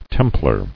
[Tem·plar]